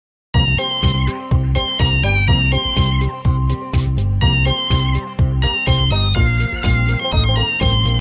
Kitten Meow song Brawl Stars sound effects free download